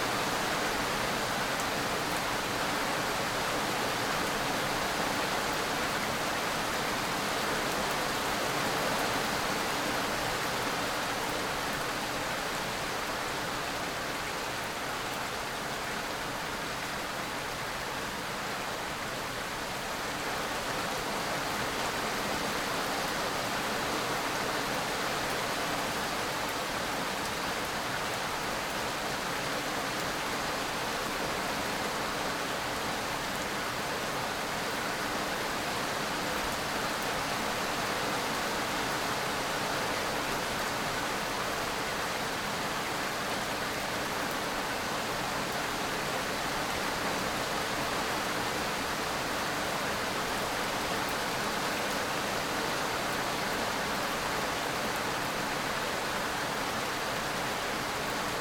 CHQ_rain_ambient.ogg